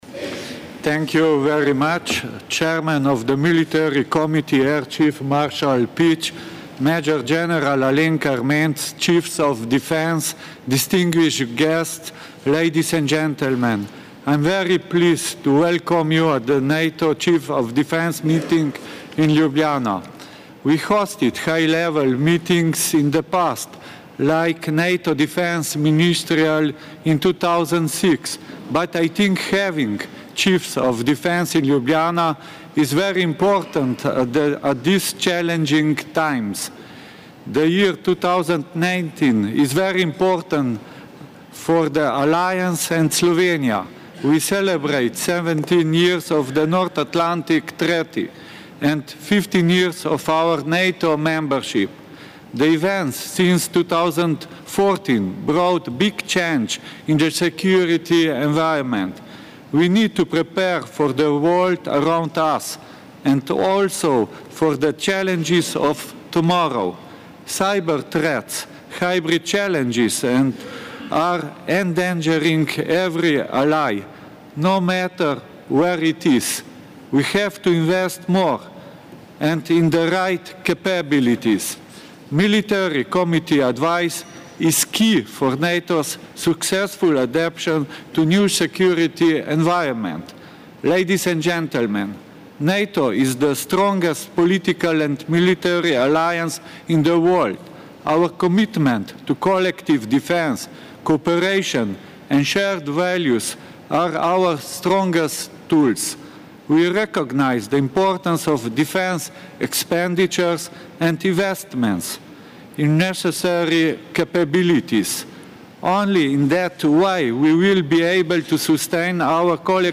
Military Committee Conference in Chiefs of Defence Session - Ljubljana, Slovenia
Press statement by Major General Alenka Ermenc, Chief of the General Staff of the Slovenian Armed Forces